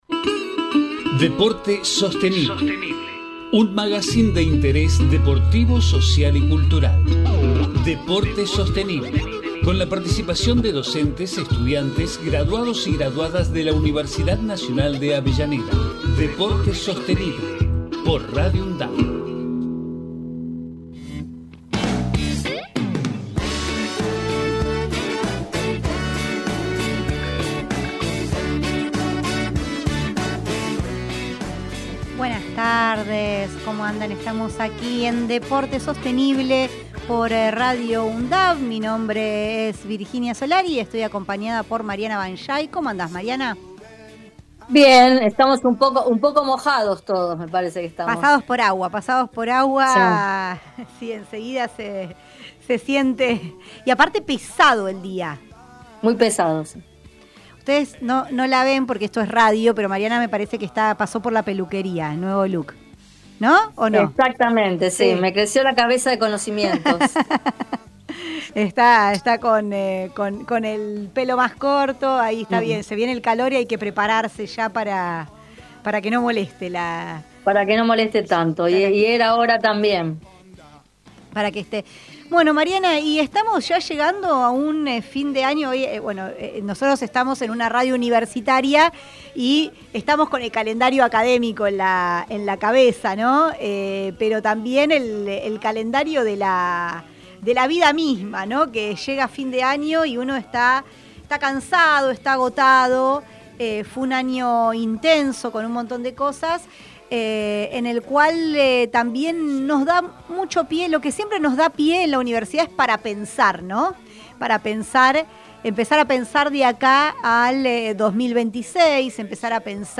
DEPORTE SOSTENIBLE Texto de la nota: En cada programa se busca abordar la vinculación estratégica entre gestión deportiva, desarrollo sostenible, salud, cultura, medio ambiente e inclusión social, realizando entrevistas, columnas especiales, investigaciones e intercambio de saberes. Magazine de interés deportivo, social y cultural que se emite desde septiembre de 2012.